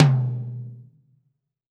PTOM 4.wav